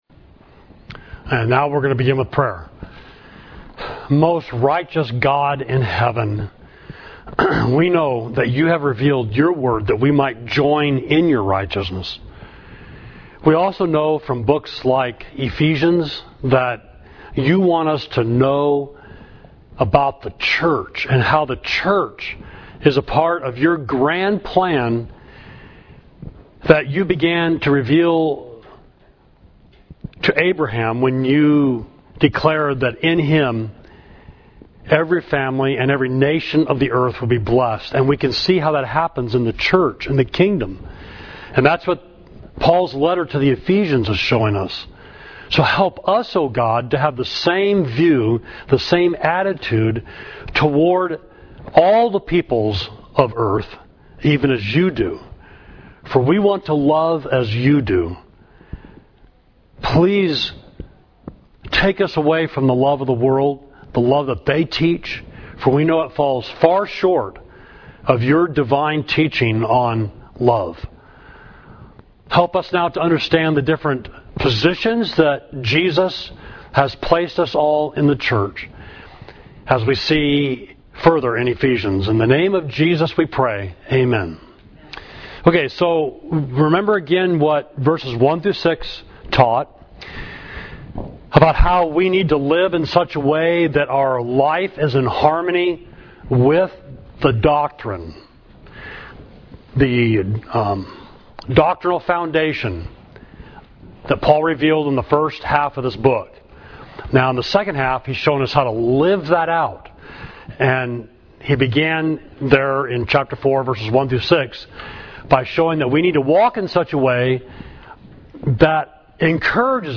Class: He Gave Gifts, Ephesians 4.7–16